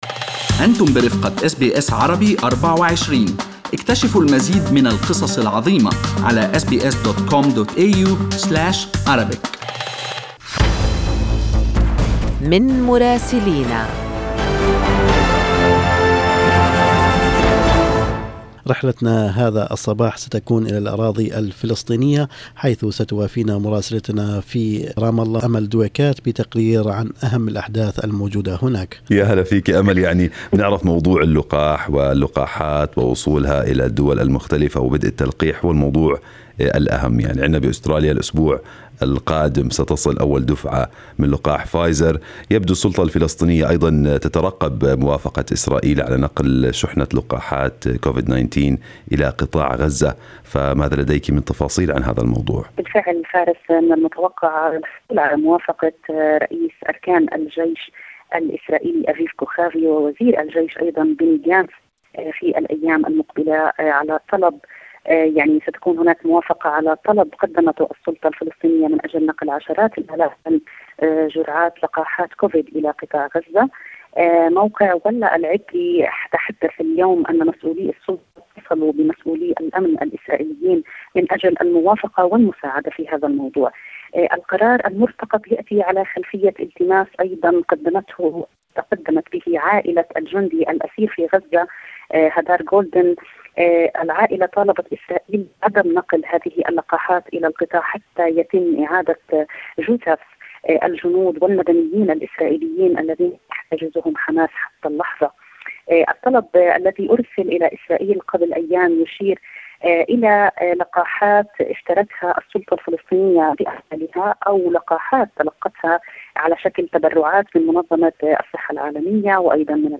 من مراسلينا: أخبار الأراضي الفلسطينية في أسبوع 15/2/2021